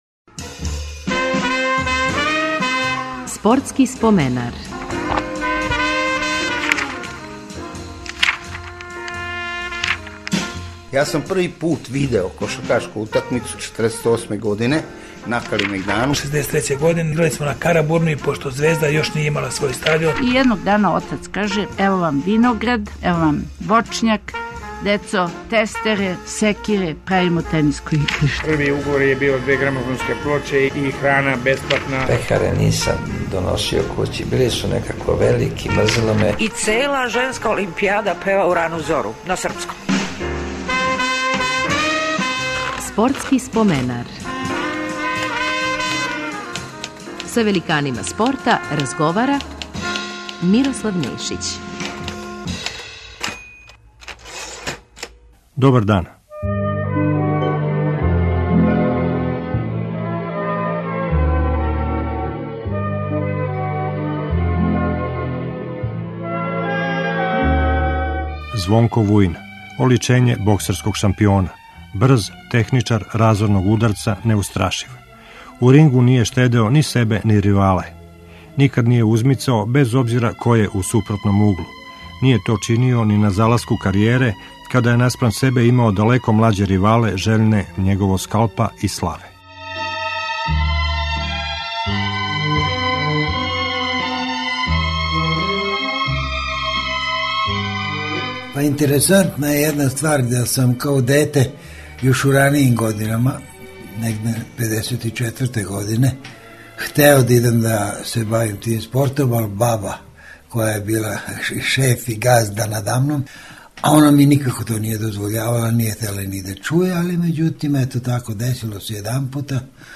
Репризираћемо разговор са боксером Звонком Вујином. Боксом је почео да се бави у 13. години, а две године касније постао је репрезентативац Југославије.